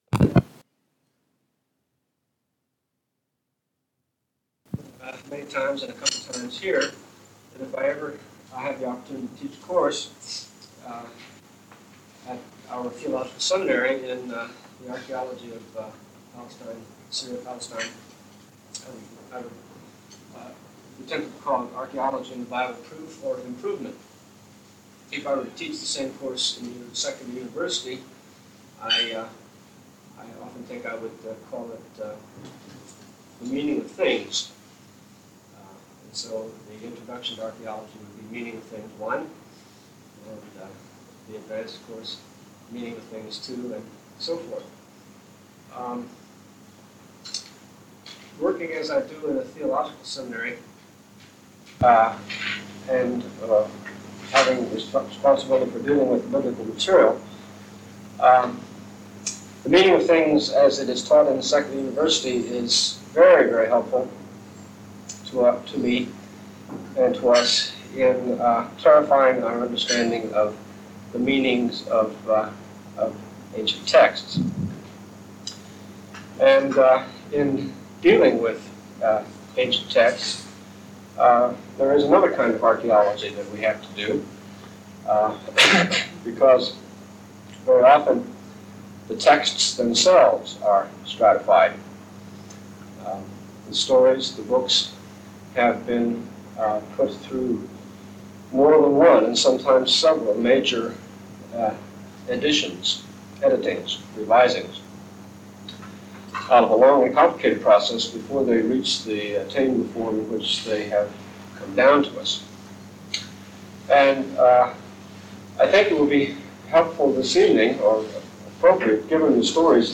Archaeology and the Book of Joshua and Judges, Lecture #3